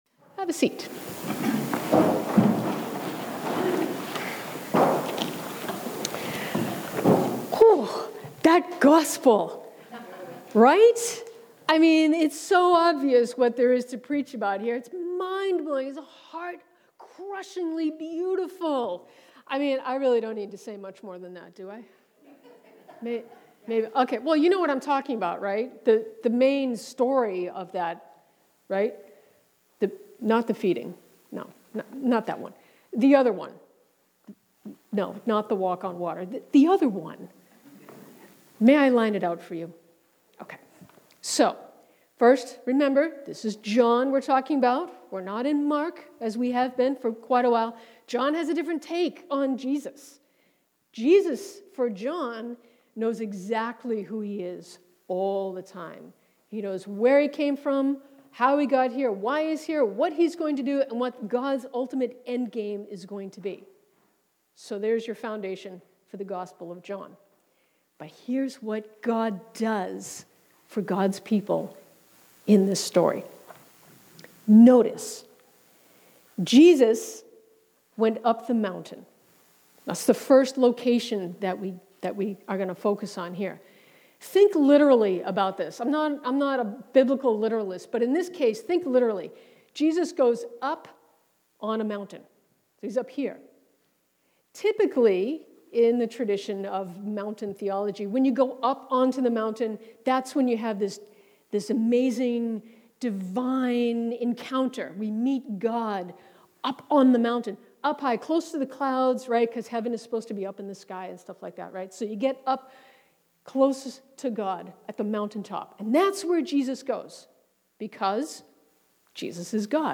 Sermons | St. David and St. Paul Anglican Church